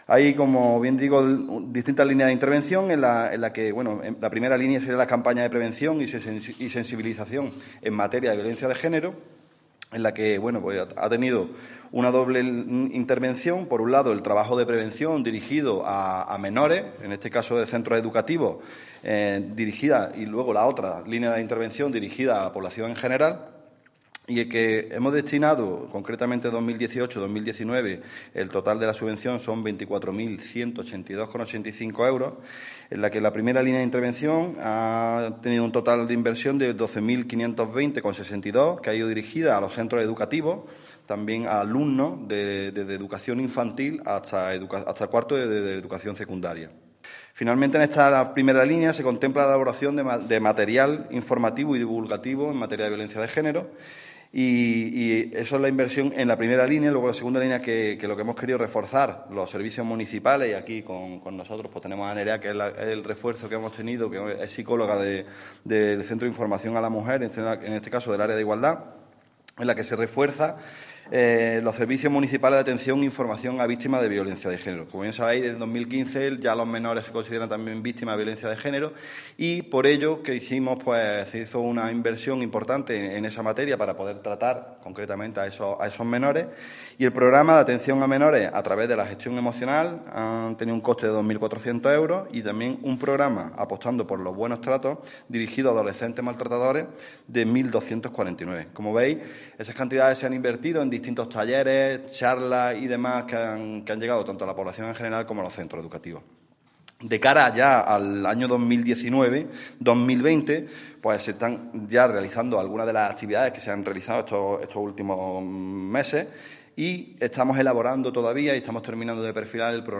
El teniente de alcalde delegado de Programas Sociales, Igualdad, Cooperación Ciudadana y Vivienda, Alberto Arana, ha realizado hoy en rueda de prensa un balance sobre las actividades promovidas por el Ayuntamiento de Antequera a través de los fondos del Pacto de Estado contra la Violencia de Género.